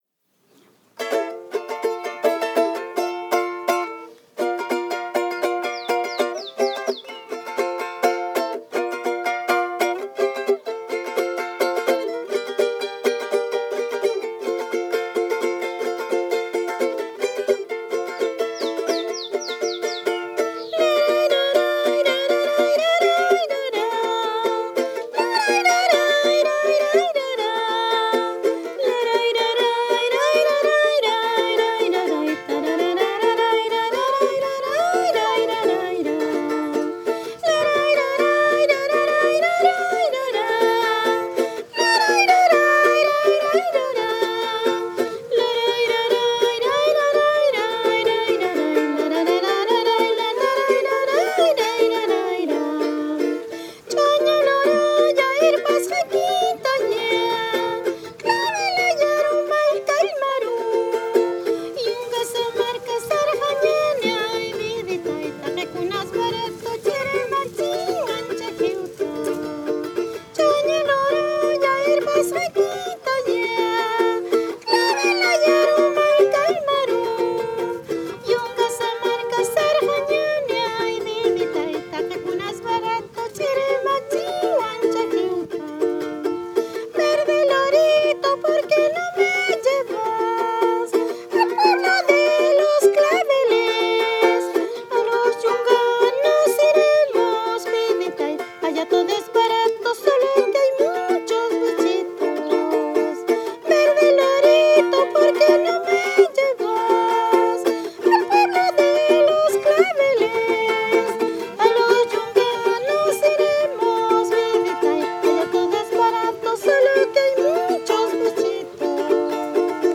Música aymara (La Huayca, Tarapacá)
Música tradicional
Música vocal